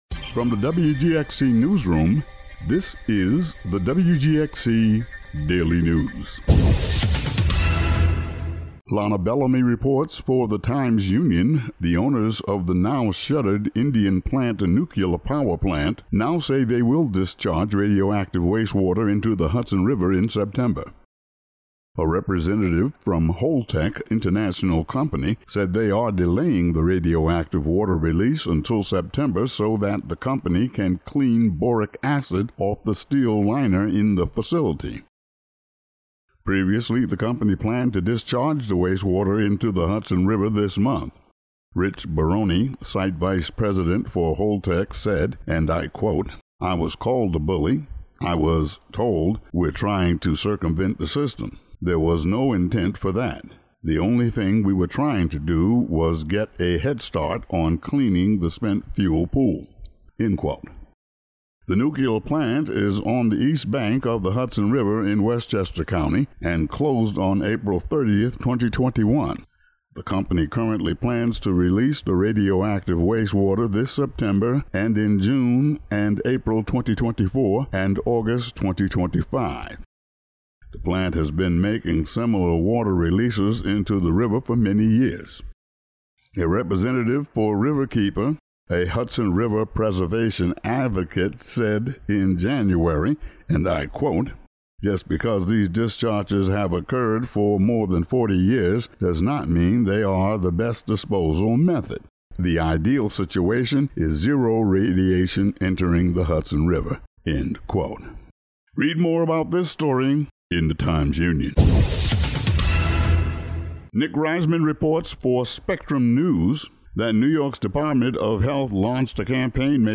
Today's daily local audio news.